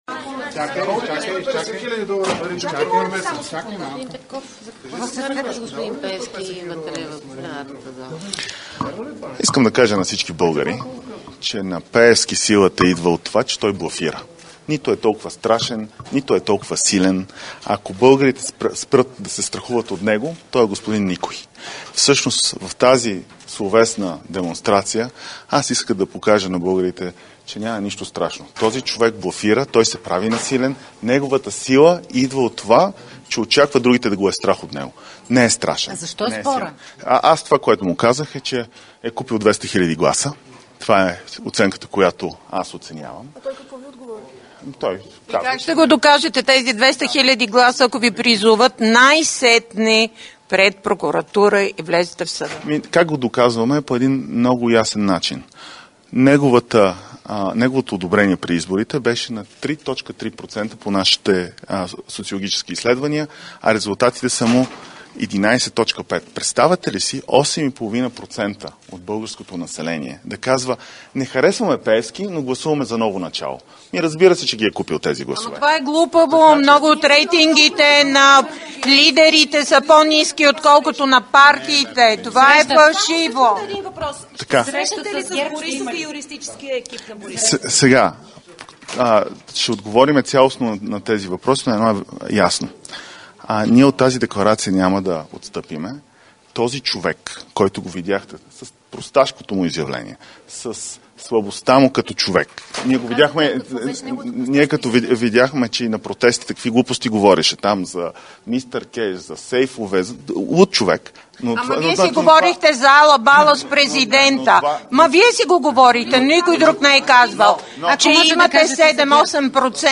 11.50 - Брифинг на съпредседателя на ПП Кирил Петков за скандала с Делян Пеевски. - директно от мястото на събитието (Народното събрание)